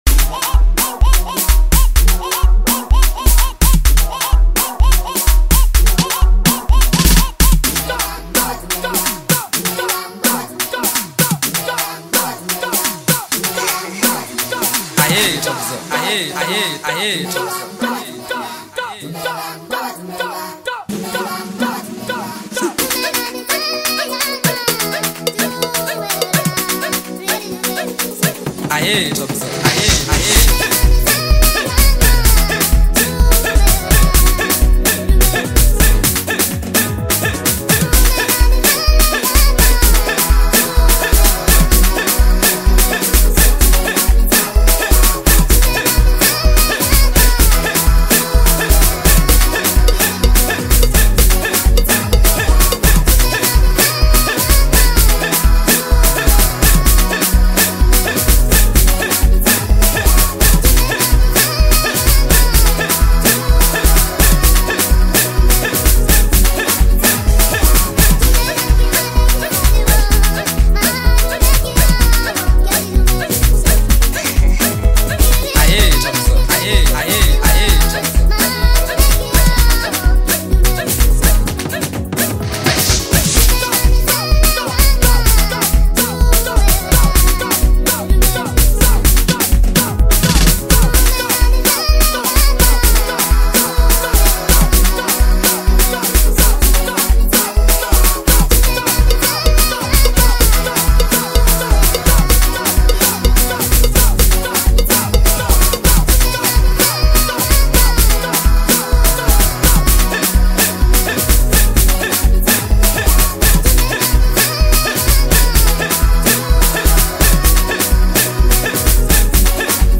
is a high energy remixed version
The remix features an upbeat tempo infectious rhythms